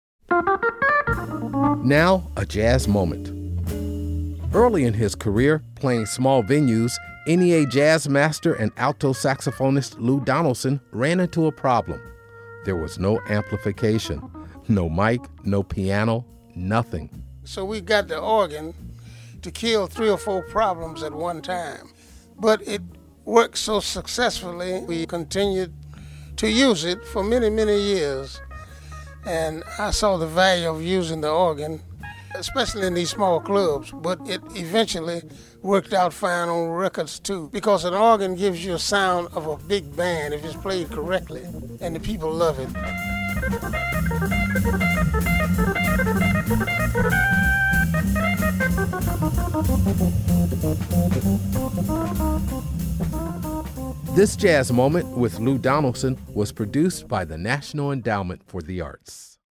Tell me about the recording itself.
Audio excerpt from the album, Live on the QE2, used courtesy of Chiaroscuro Records